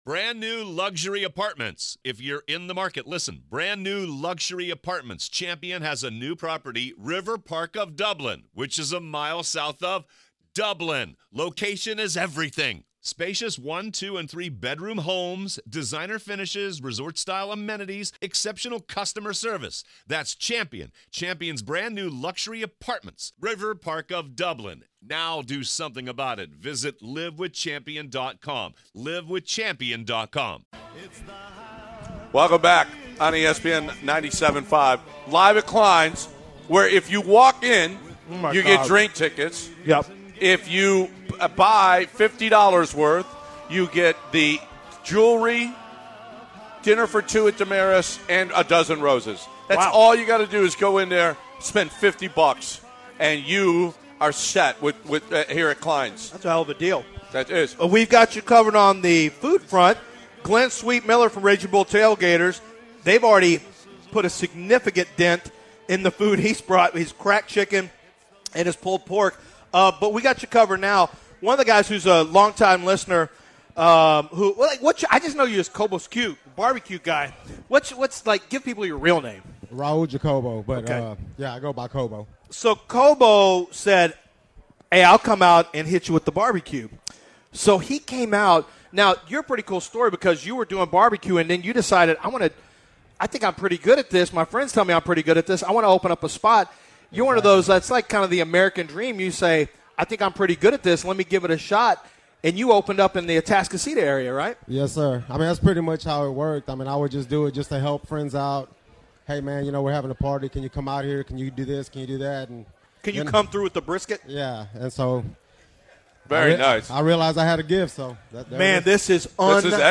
In the second hour of the show, the guys continue the live broadcast from the station Christmas party at Klein’s Fine Jewelers.